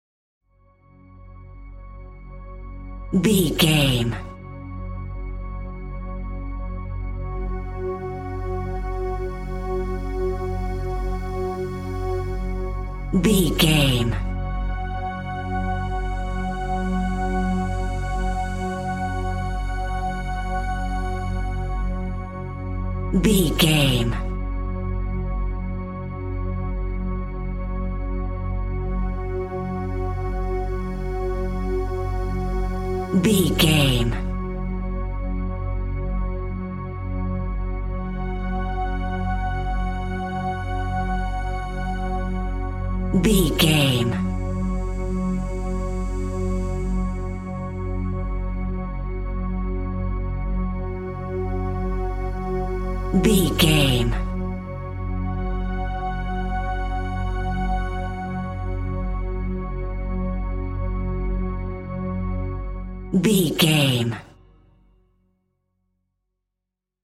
In-crescendo
Thriller
Aeolian/Minor
F#
Slow
scary
ominous
dark
eerie
synthesiser
horror
Synth Pads
atmospheres